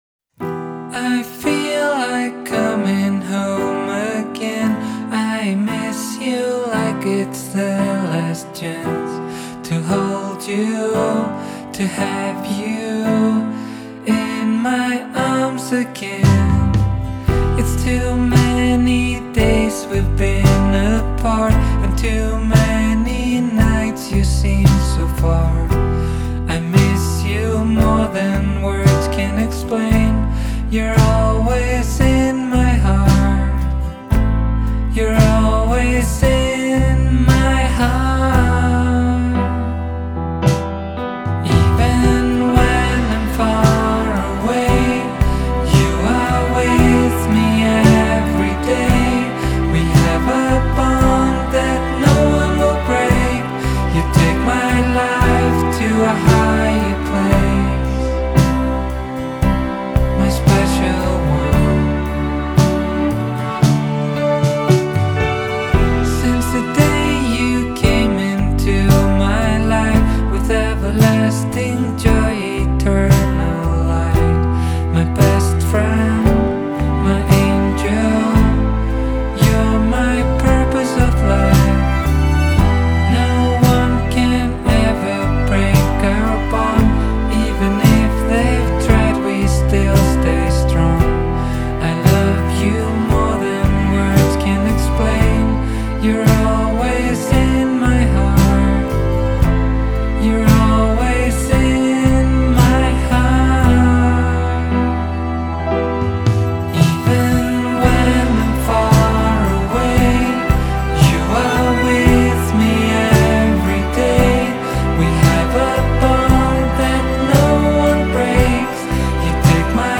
Genre: Electronic, Indie Pop